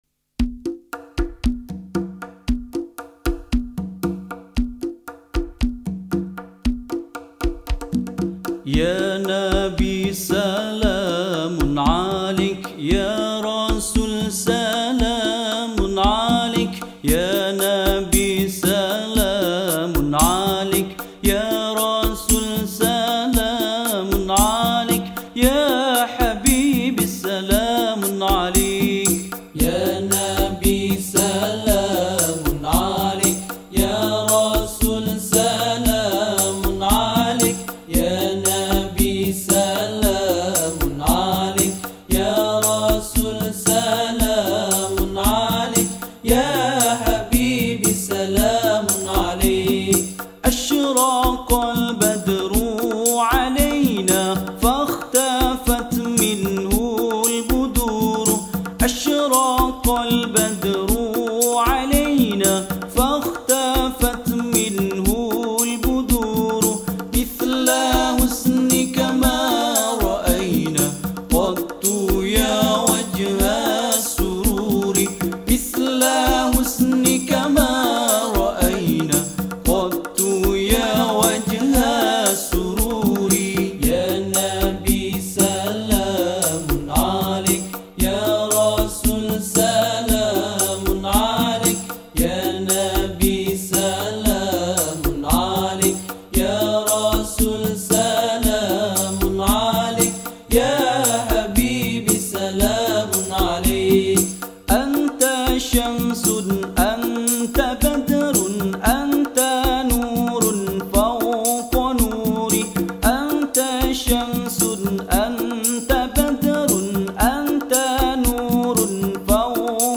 Nasyid Songs
Islamic Dakwah Song
Skor Angklung